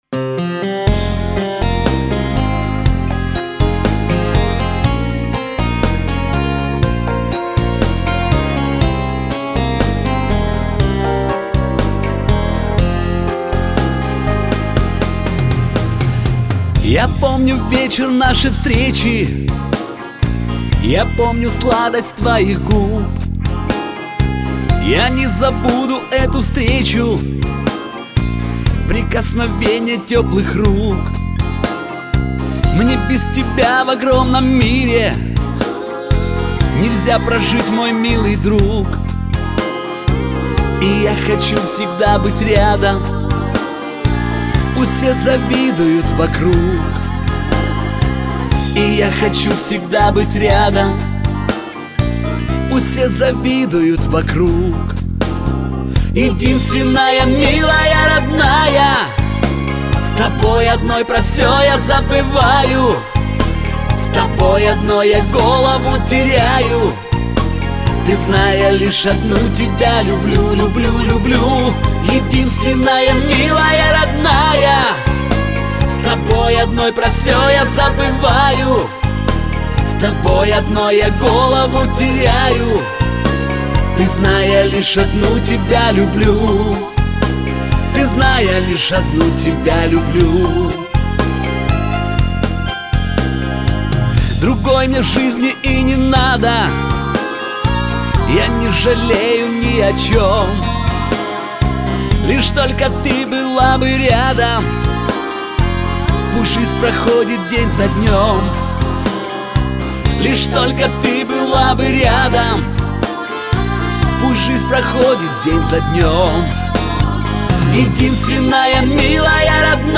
Всі мінусовки жанру Pop
Плюсовий запис